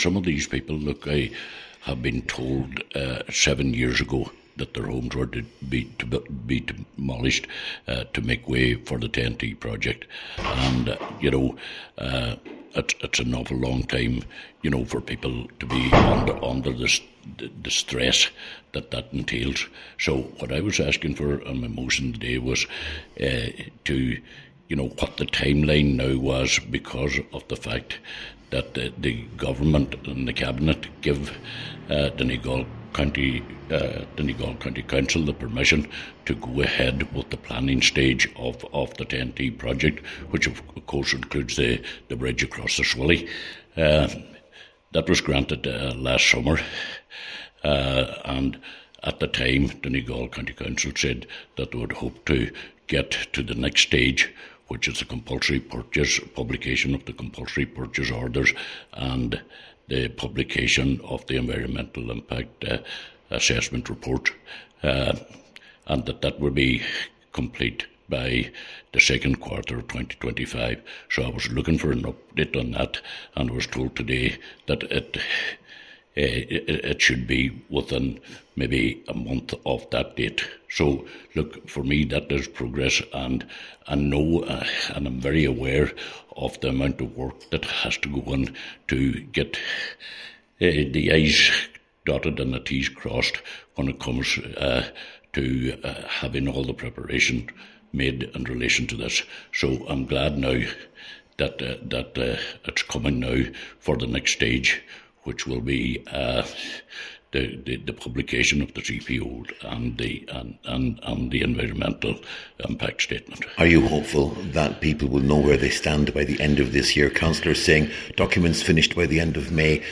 Cllr Coyle says it’s vital that this progresses and people know where they stand: